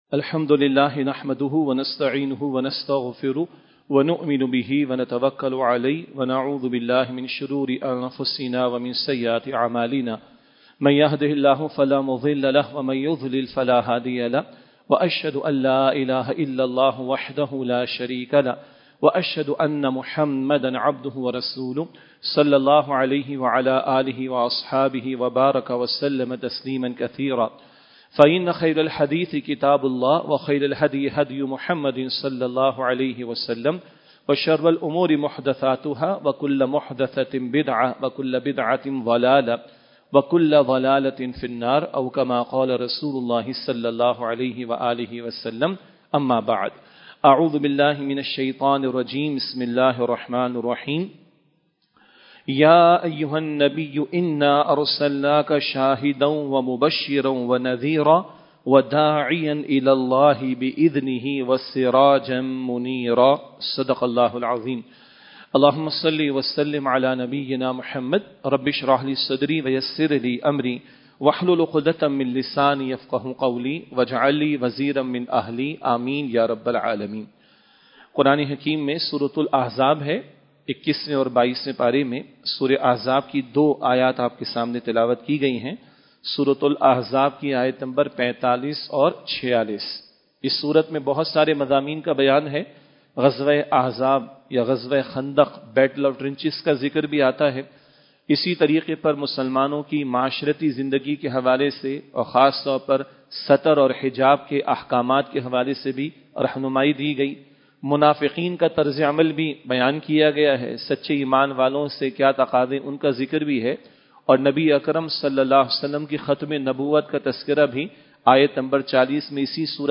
Khutbat-e-Jummah (Friday Sermons)